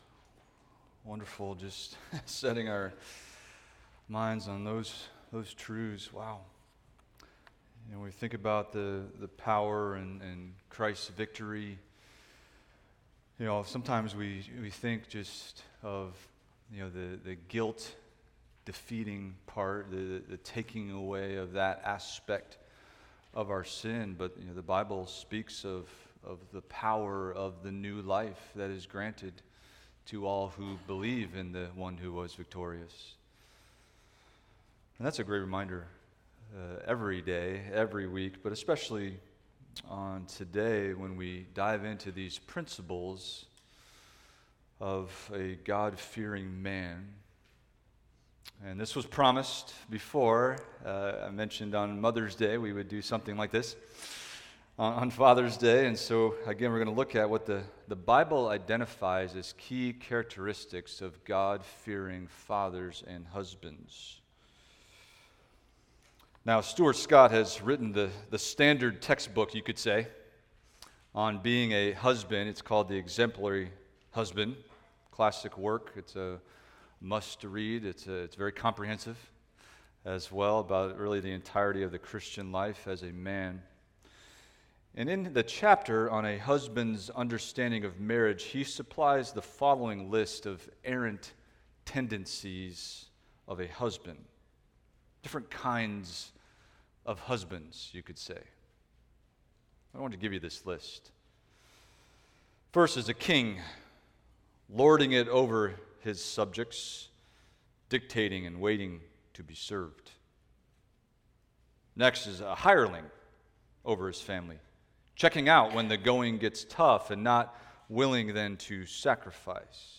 Expository Preaching from various scriptures – A Man Who Fears God
A Man Who Fears God Sermon Outline